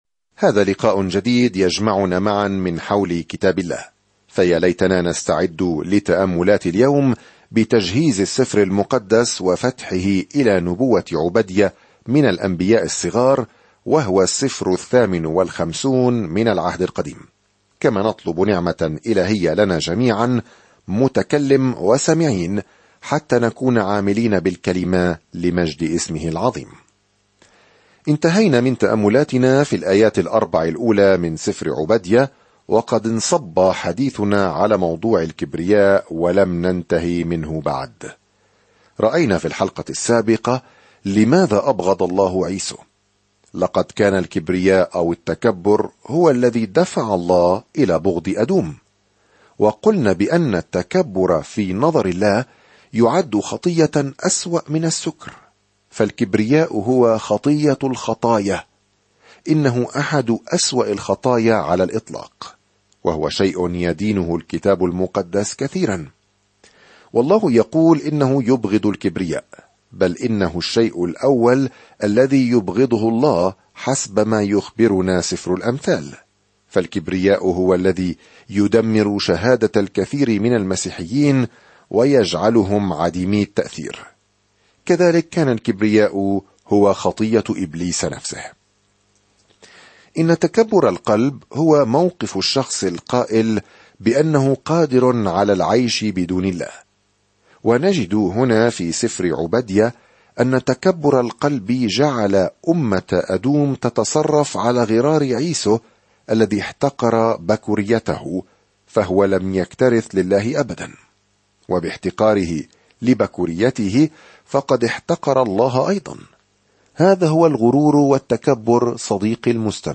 هكذا حذر عوبديا الأمم المجاورة لإسرائيل. سافر يوميًا عبر عوبديا وأنت تستمع إلى الدراسة الصوتية وتقرأ آيات مختارة من كلمة الله.